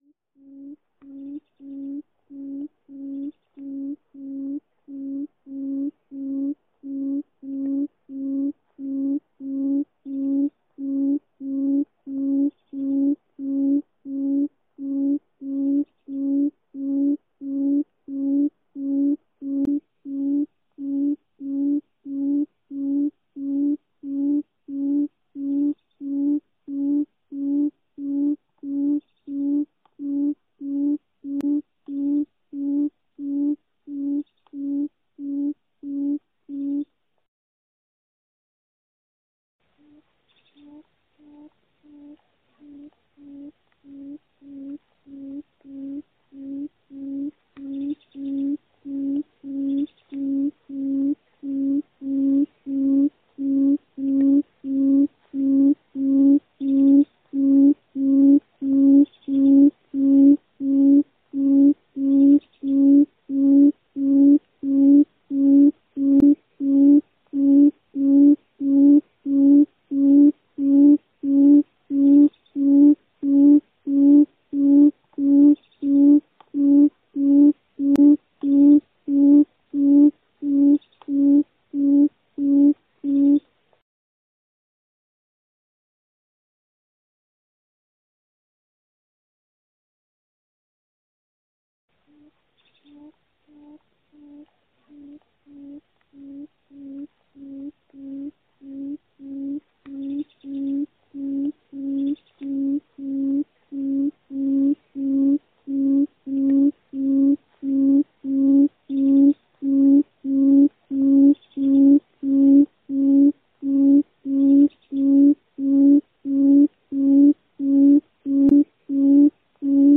Tiếng chim Cút mái
Tiếng động vật 379 lượt xem 17/02/2026
File âm thanh này mô phỏng chính xác giọng kêu đặc trưng của chim cút mái trong tự nhiên, giúp tạo nên một không gian âm thanh gần gũi và sống động.
Tiếng chim Cút mái thường có âm thanh trầm, đều đặn và có khả năng vang xa trong các bụi rậm hoặc cánh đồng. File audio đã được thu âm và xử lý kỹ thuật để đảm bảo không bị lẫn tạp âm từ gió hay tiếng máy móc.